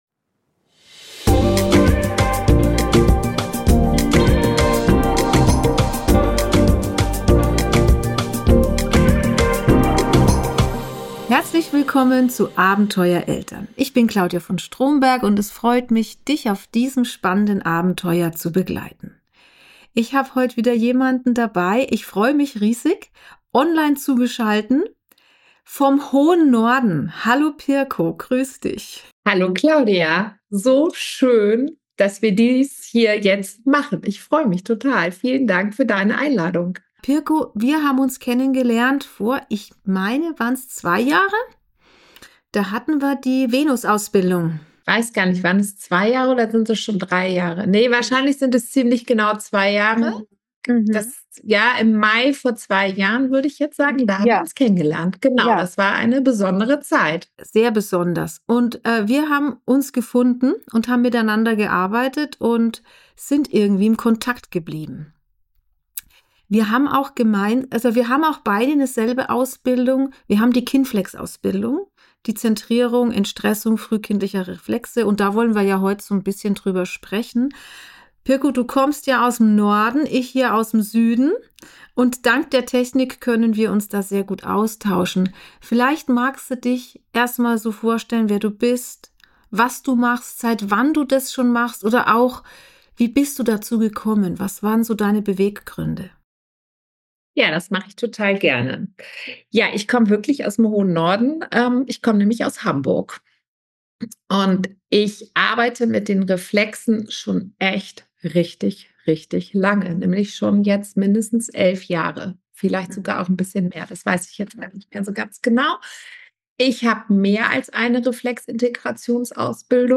Ein lockerer, aber sehr erkenntnisreicher Austausch, der zeigt, wie wichtig dieses Thema wirklich ist.